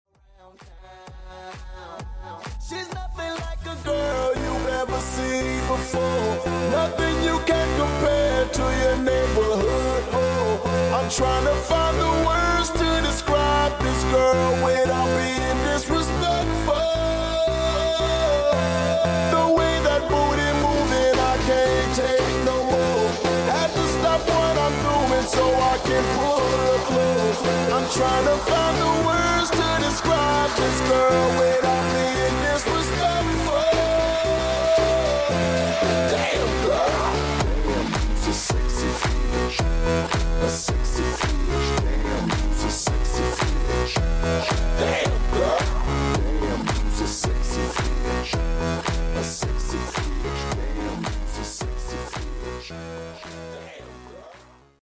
(SLOWED & REVERB)